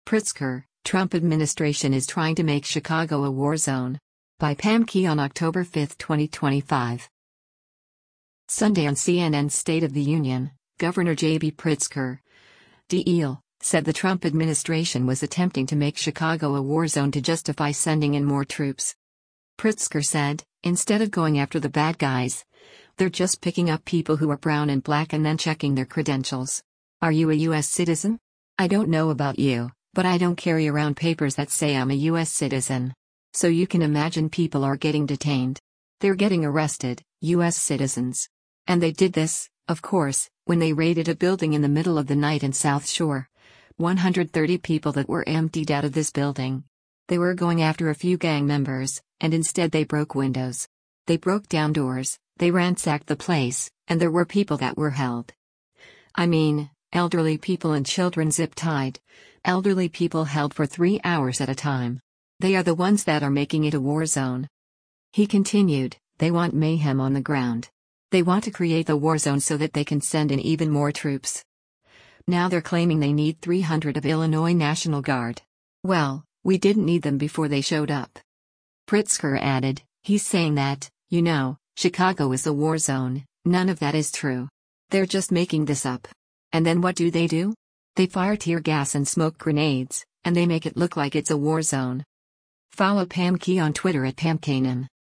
Sunday on CNN’s “State of the Union,” Gov. JB Pritzker (D-IL) said the Trump administration was attempting to make Chicago a war zone to justify sending in more troops.